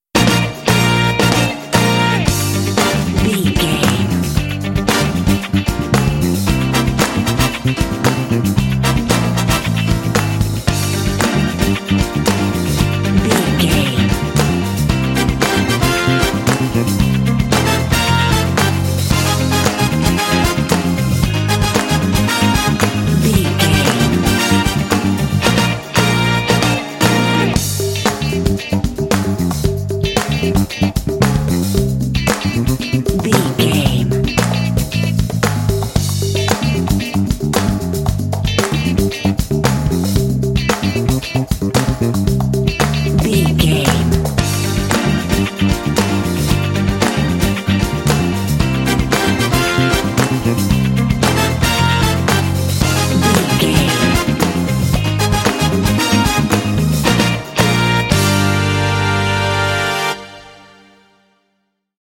Uplifting
Aeolian/Minor
funky
groovy
lively
energetic
bass guitar
electric guitar
brass
electric organ
piano
drums
strings
Funk
jazz